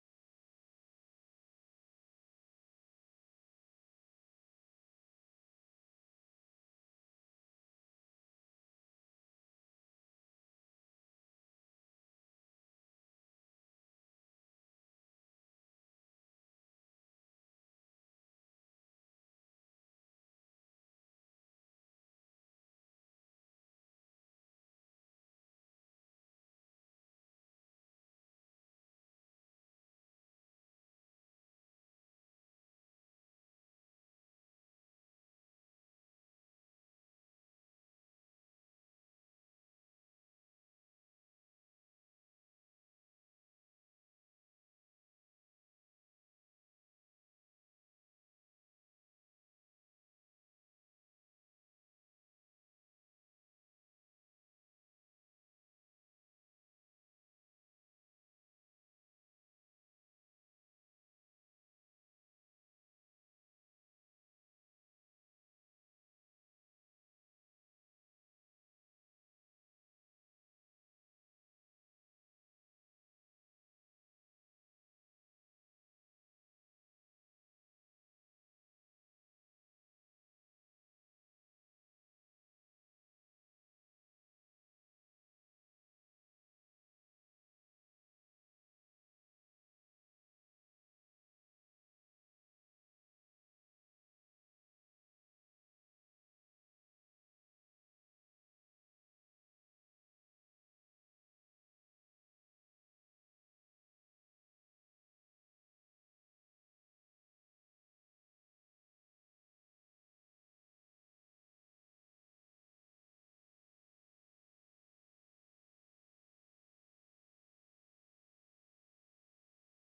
Responsibility – Grace Sermon
Responsibility-Grace-Sermon-Audio-CD.mp3